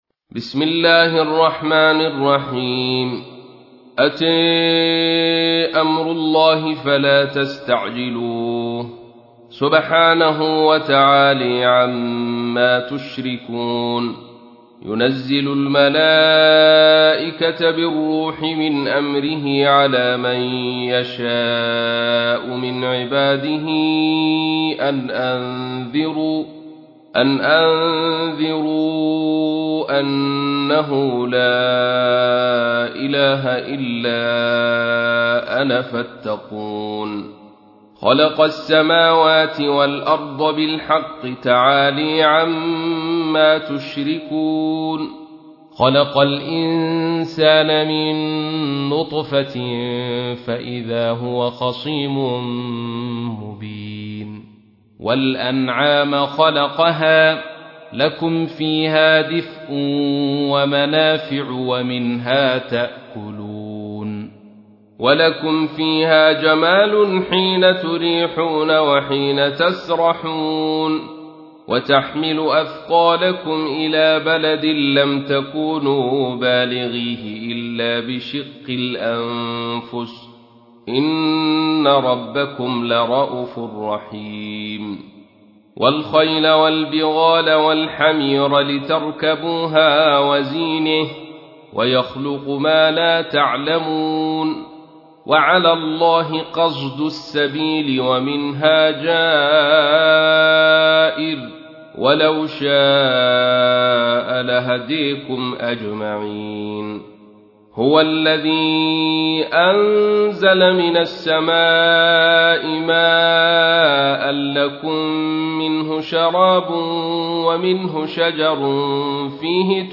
16. سورة النحل / القارئ